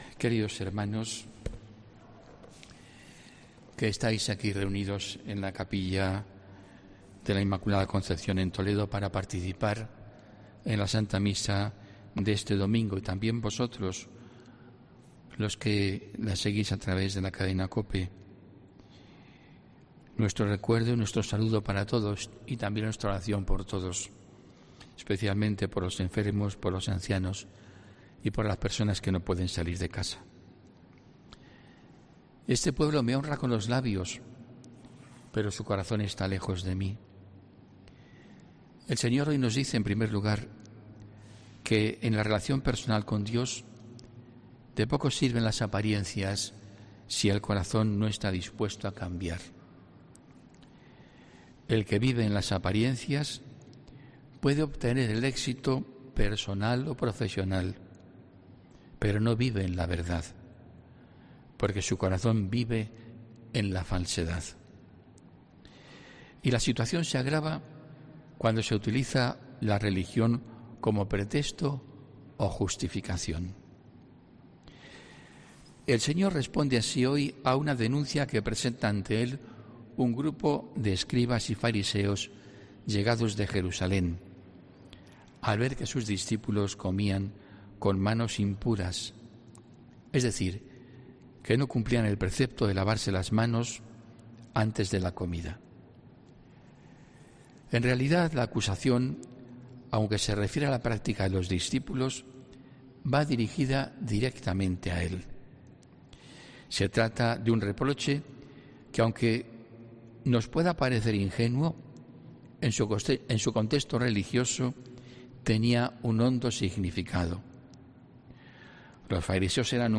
HOMILÍA 29 AGOSTO 2021